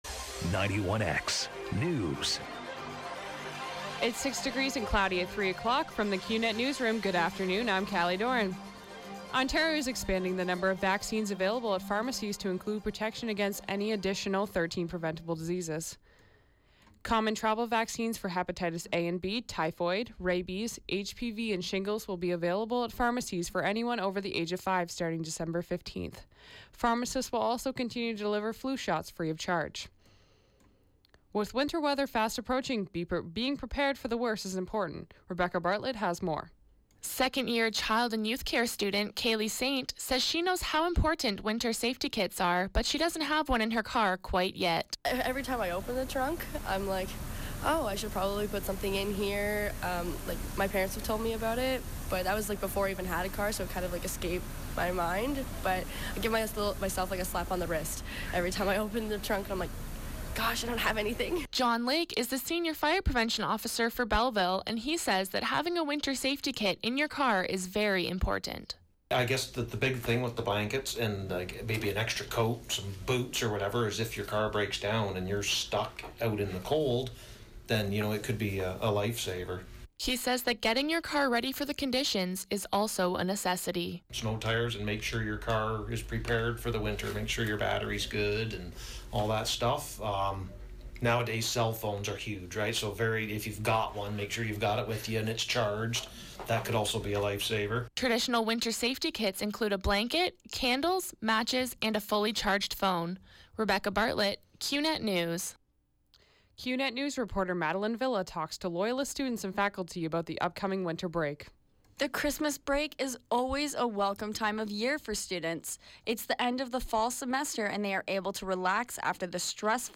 91X FM Newscast – Thursday, Dec. 1, 2016, 3 p.m.